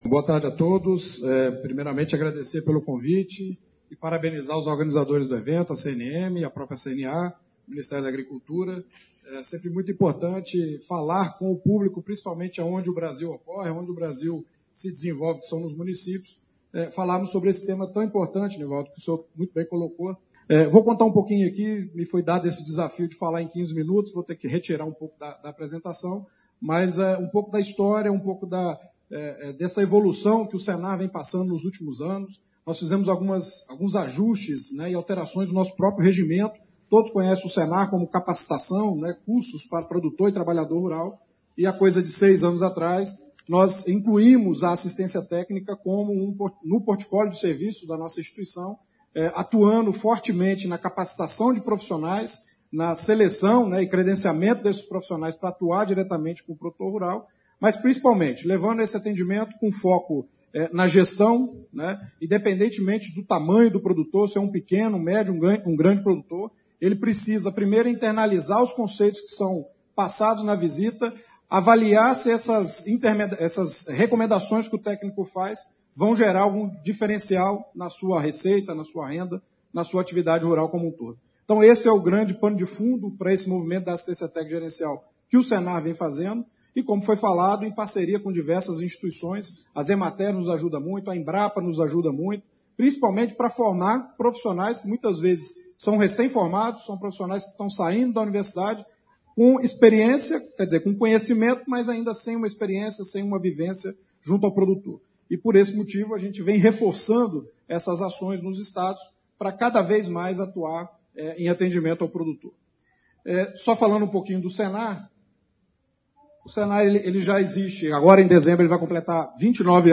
Congresso Brasileiro de Gestores da Agropecuária